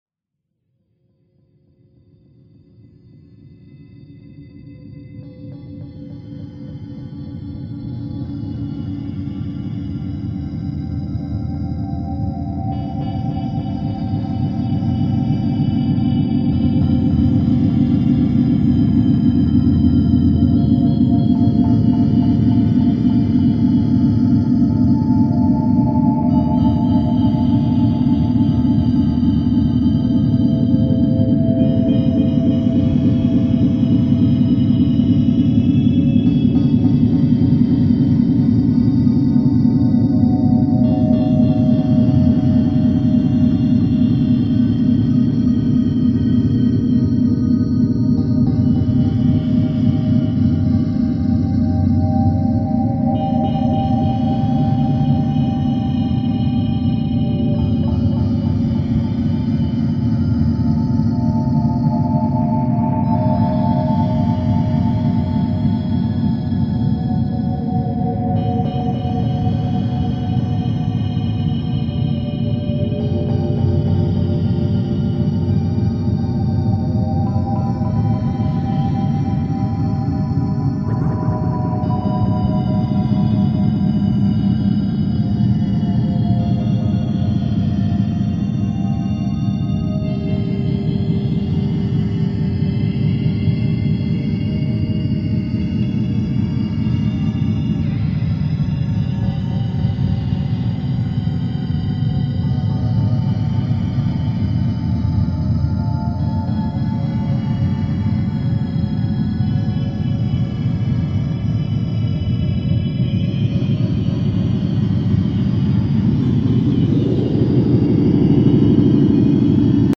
a very meditative and deeply atmospheric work
haunting minimal ambient electronics